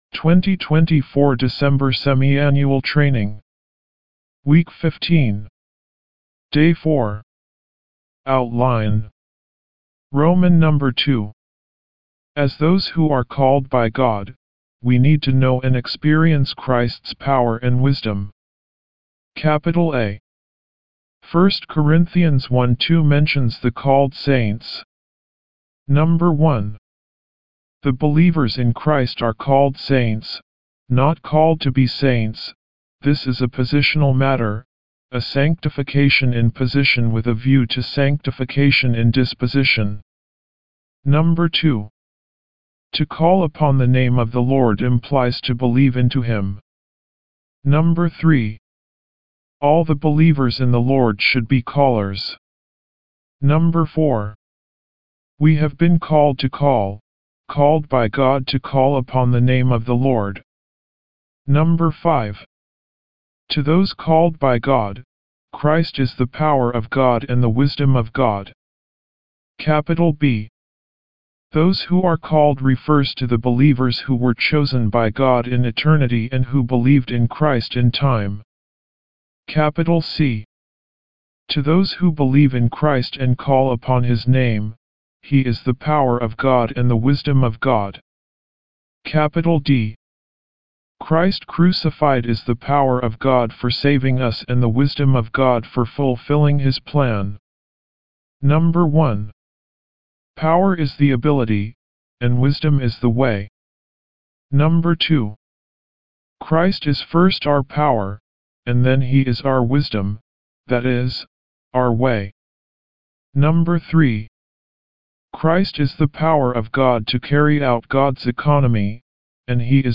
202307晨興聖言中英文朗讀
Morning Revival Recitation